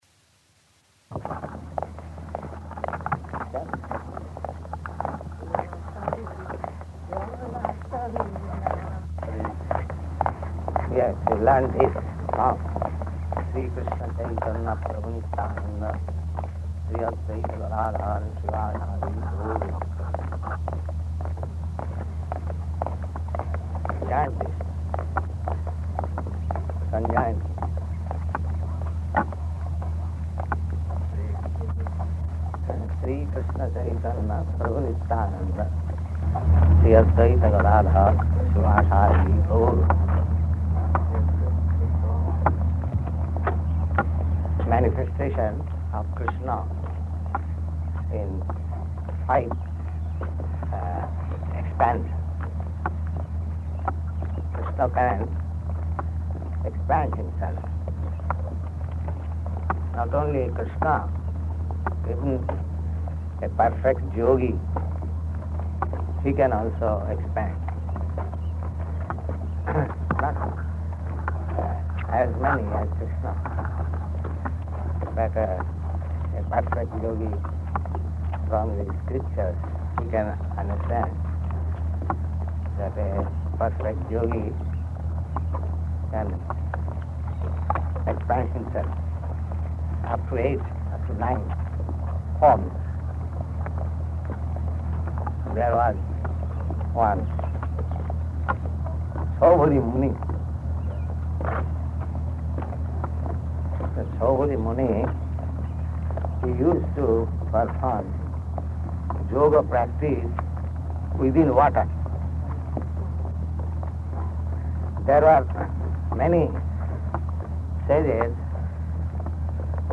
Morning Walk at Stow Lake